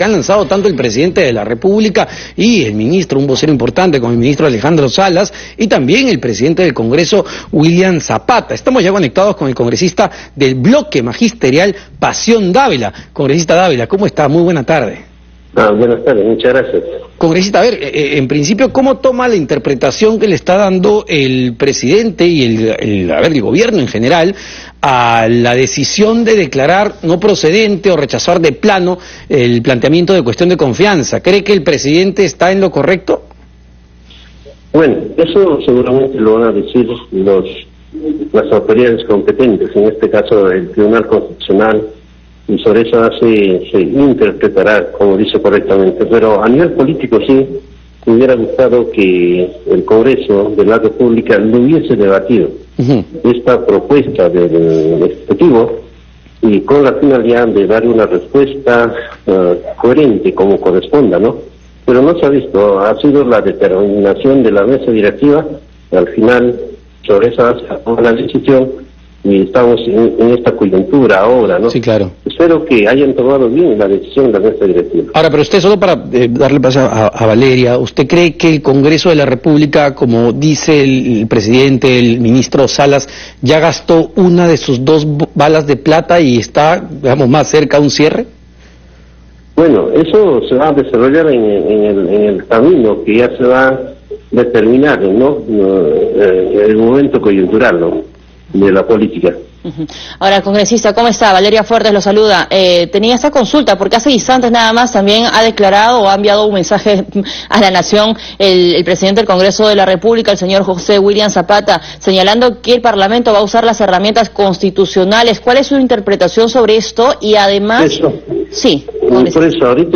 Entrevista al congresista Pasión Dávila